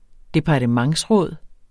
Udtale [ -ˌʁɔˀð ]